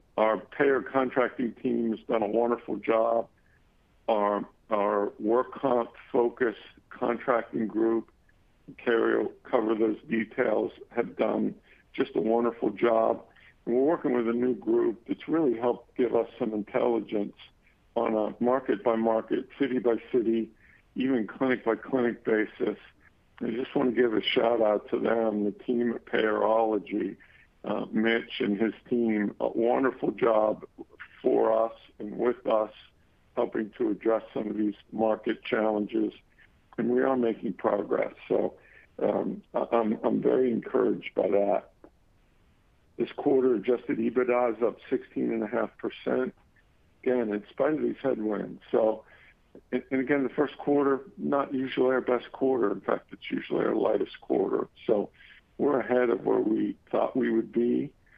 Q1 2025 USPh Earnings Call (NYSE: USPH)